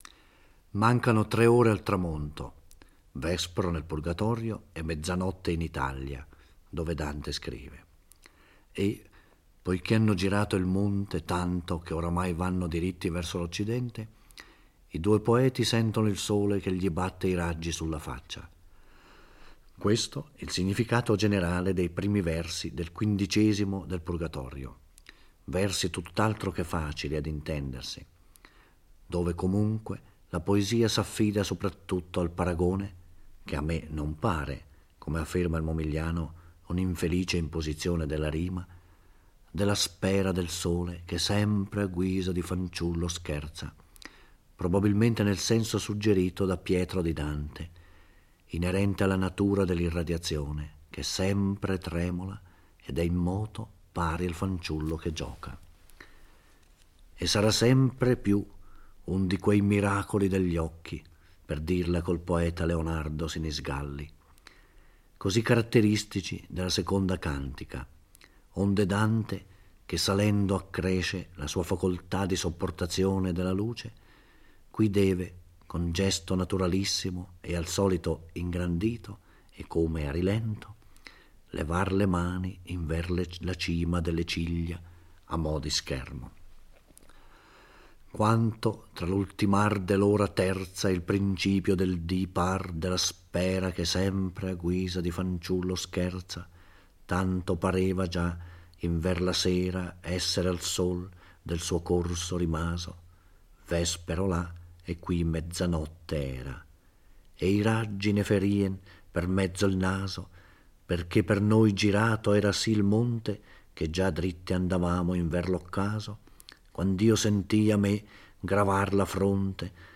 Giorgio Orelli legge e commenta il XV canto del Purgatorio. Dante e Virgilio avanzano nel loro cammino e gli appare l'angelo della misericordia per accompagnarli alla cornice successiva, quella dedicata agli iracondi: coloro che in vita hanno ceduto con eccesso agli istinti dell'ira.